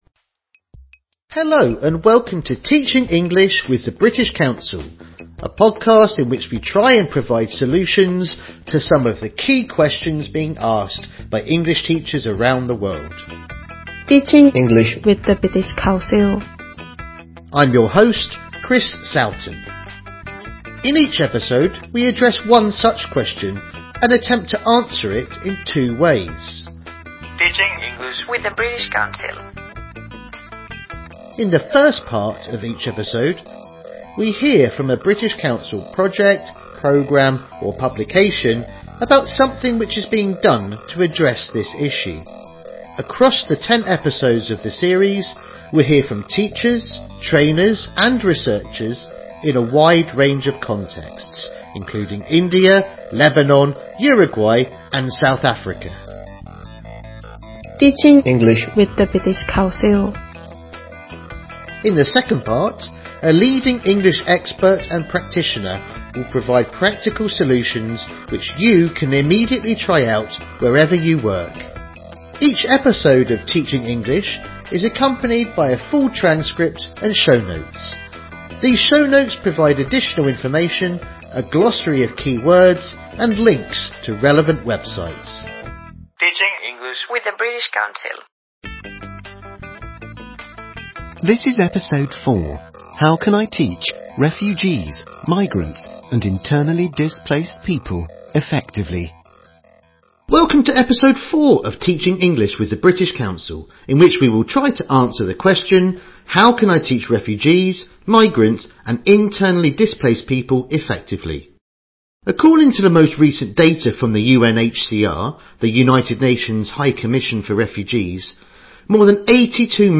British Council: TeachingEnglish - series overview In this ten-part podcast series from the British Council, we try and provide solutions to some of the key questions being asked by English teachers around the world. Each episode explores a specific topic through interviews, a focus on recent developments and reports on British Council initiatives in English language teaching.